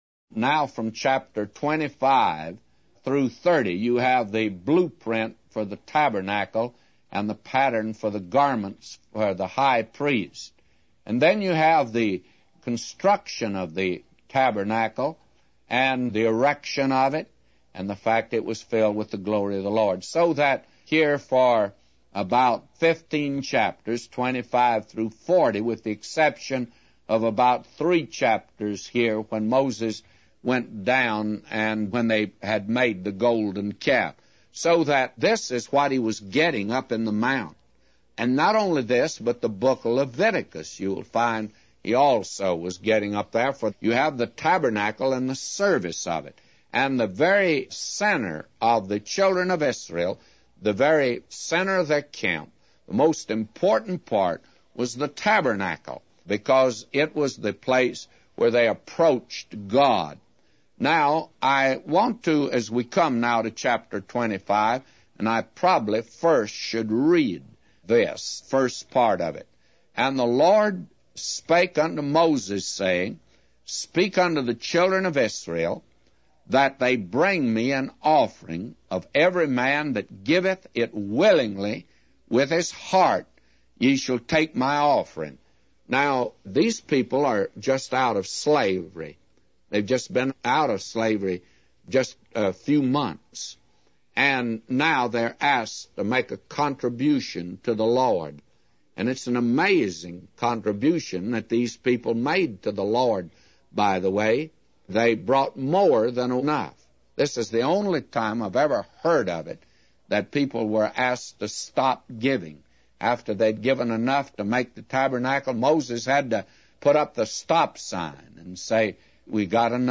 A Commentary By J Vernon MCgee For Exodus 25:1-999